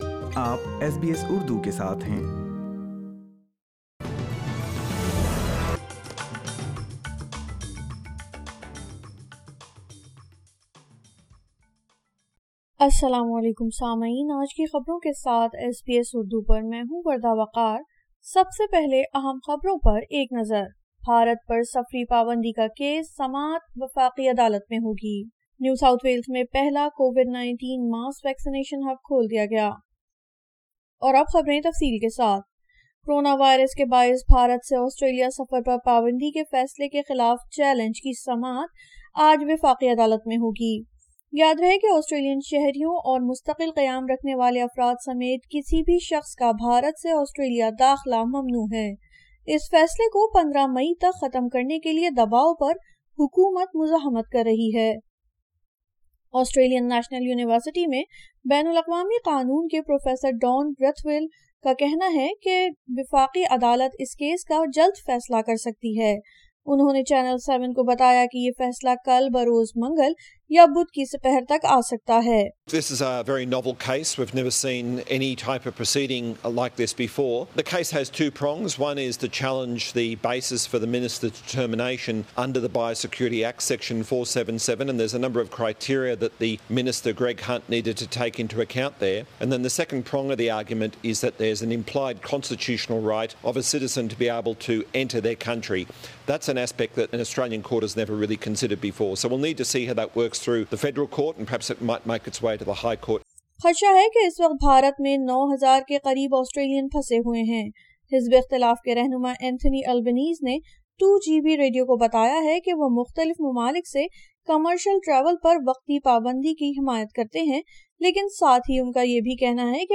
اردو خبریں 10 مئی 2021